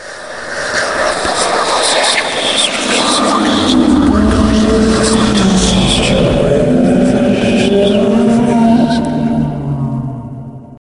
ambient_drone_10.ogg